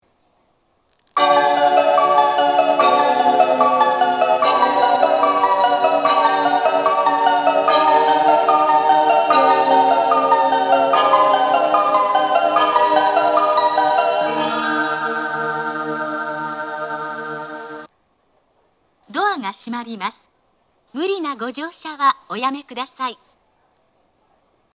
発車メロディー
フルコーラスです。